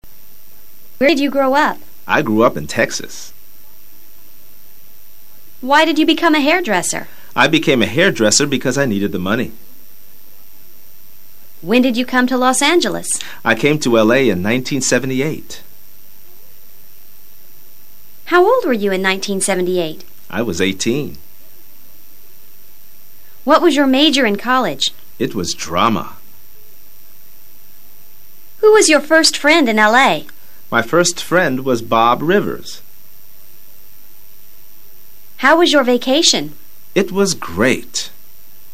Escucha al profesor leyendo preguntas en tiempo PASADO SIMPLE que comienzan con WH.